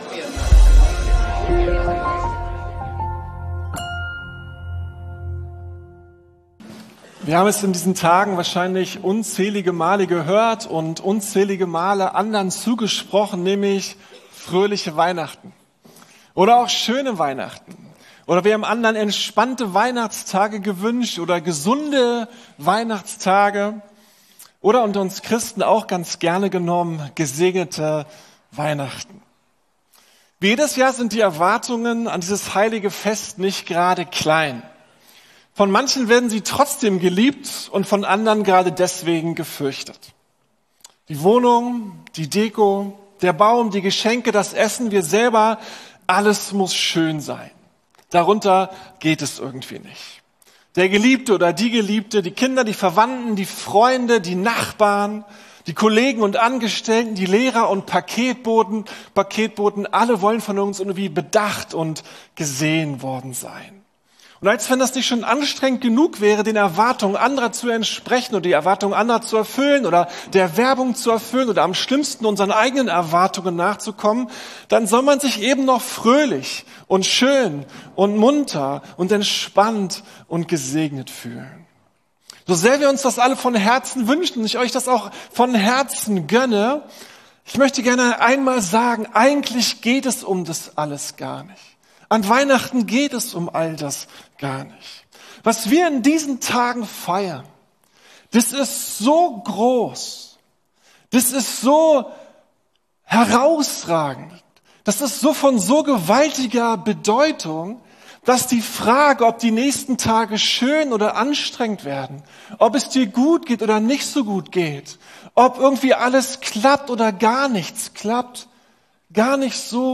Weihnachtsgottesdienst ~ Predigten der LUKAS GEMEINDE Podcast